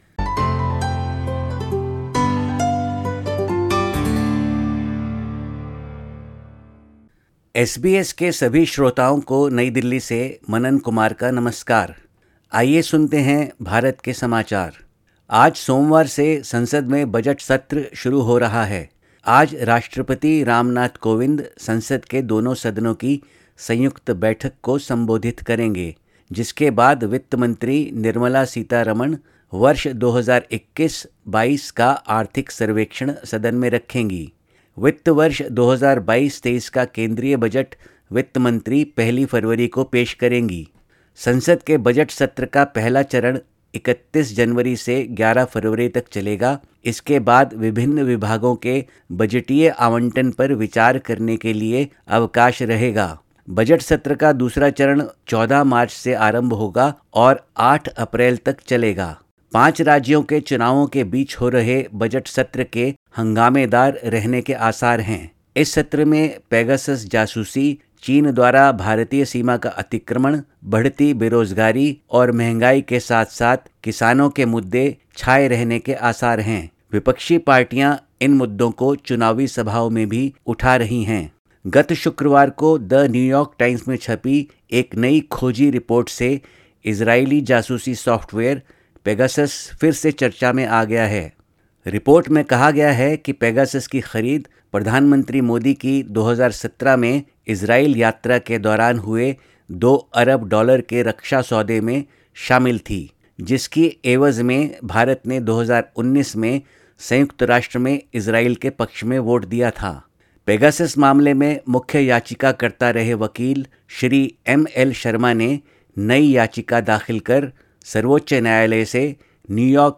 भारत के समाचार हिन्दी में